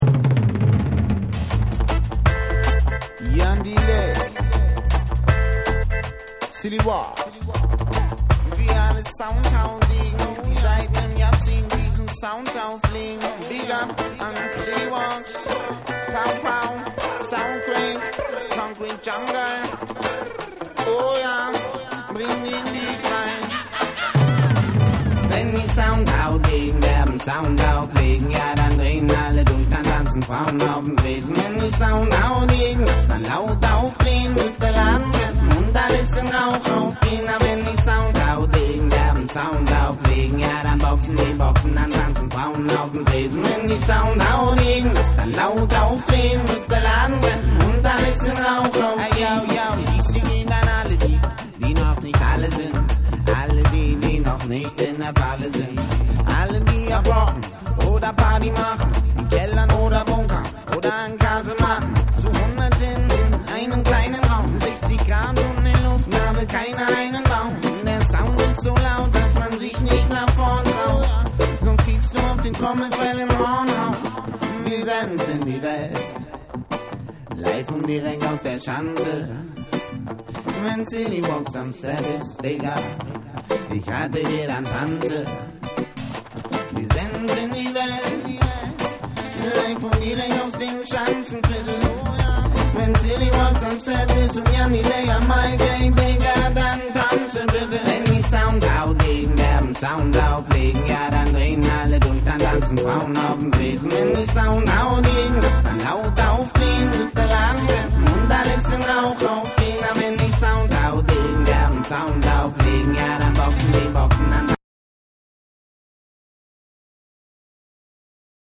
BREAKBEATS / HIP HOP